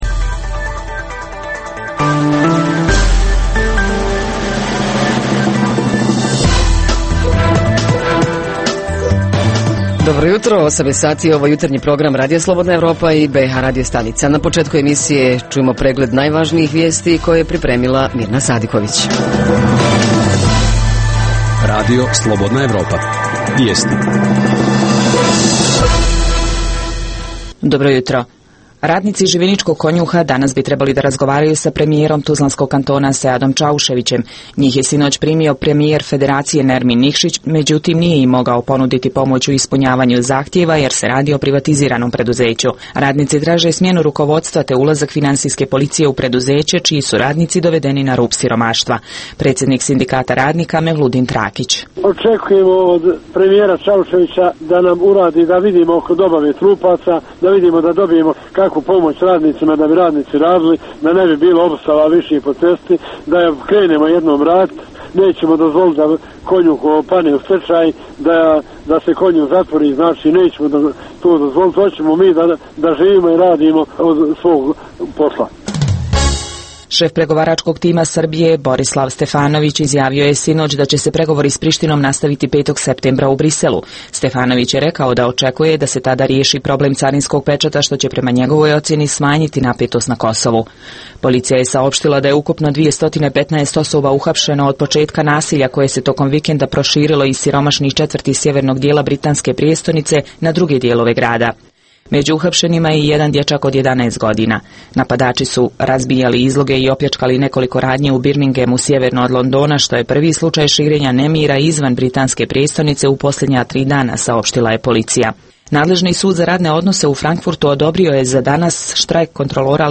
Kako rade komunalne službe tokom ljeta (odvoz smeća, čišćenje ulica, održavanje parkova itd), da li ima nekih izmijena u sistemu rada, te da li su građani zadovoljni njihovim radom i preko ljeta i inače? Reporteri iz cijele BiH javljaju o najaktuelnijim događajima u njihovim sredinama.
Redovni sadržaji jutarnjeg programa za BiH su i vijesti i muzika.